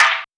Percussion #13.wav